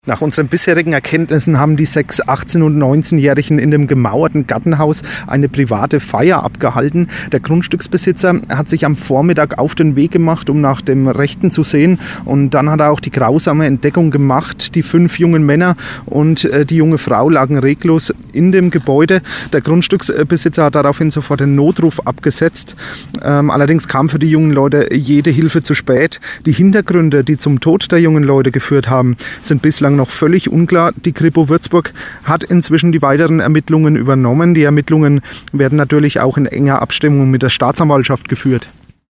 Polizeipressesprecher